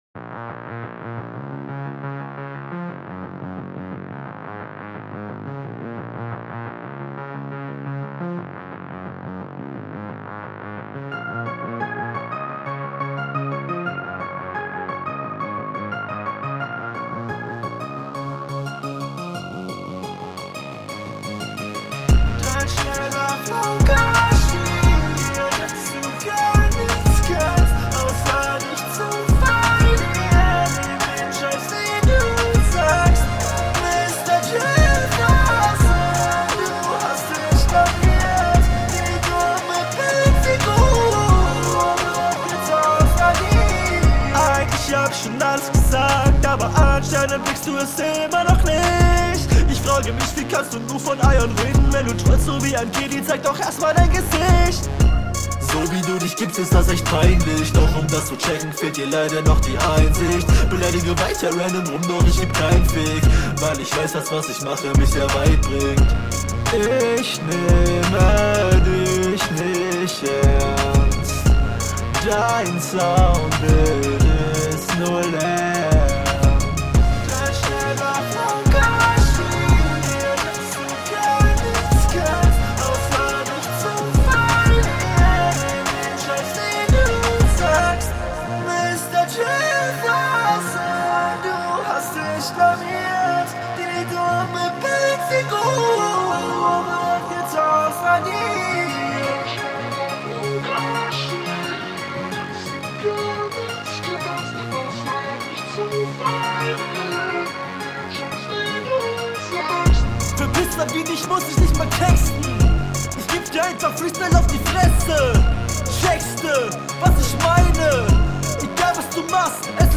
Der Autotune ist mir etwas zu krass.
Suuuper geile, catchy Melodie.